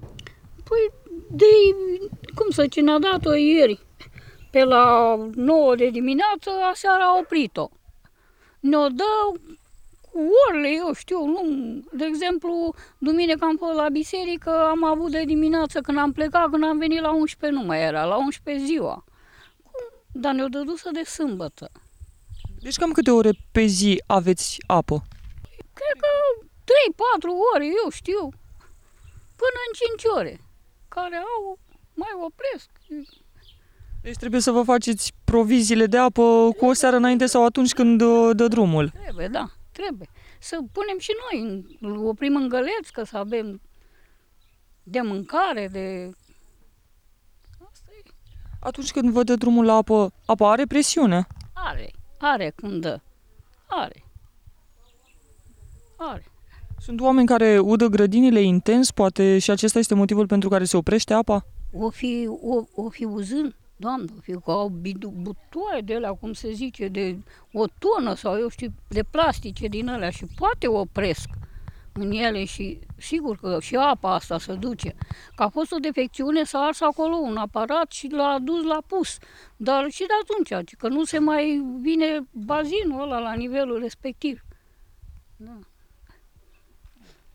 Cetățeni, Comuna Bălănești